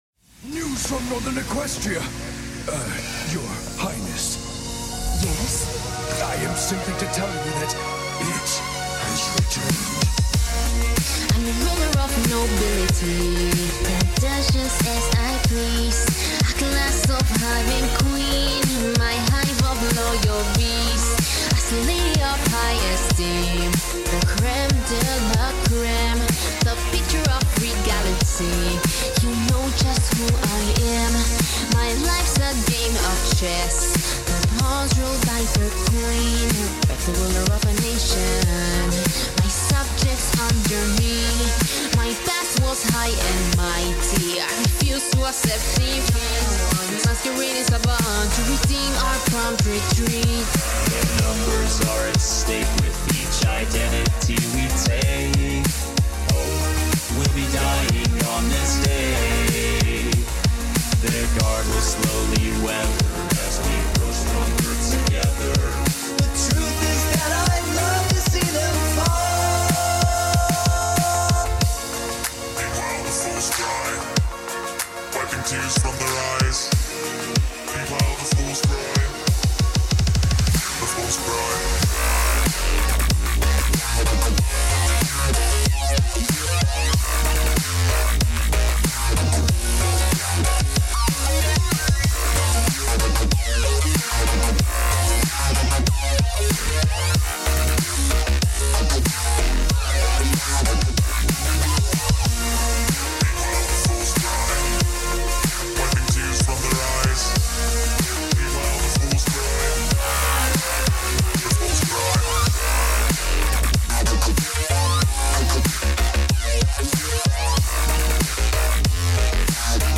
BPM - 94
Genre - Drumstep